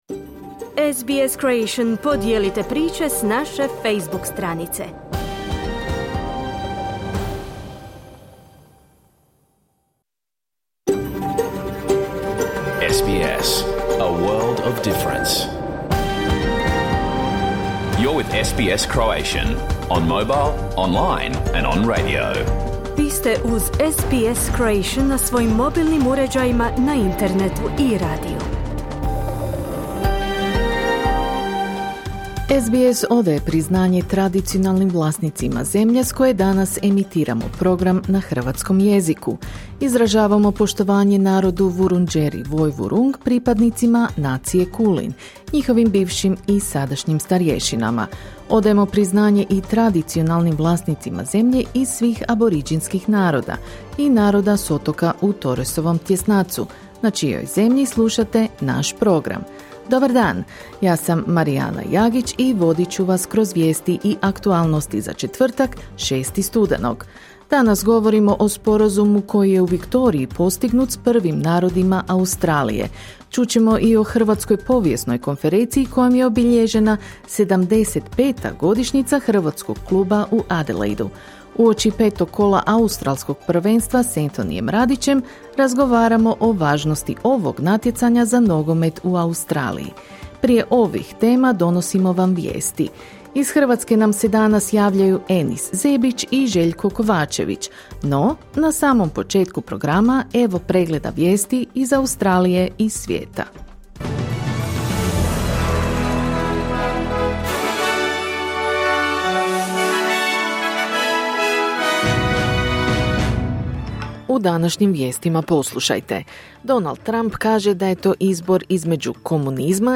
Vijesti i aktualnosti iz Australije, Hrvatske i ostatka svijeta.